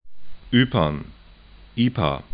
'y:pɐn